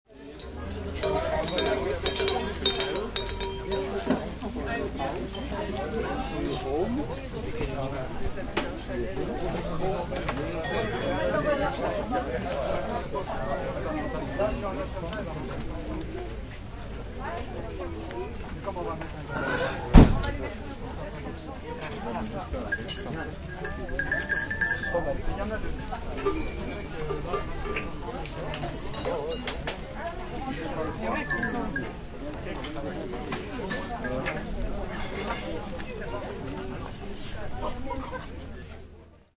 Download Fair sound effect for free.
Fair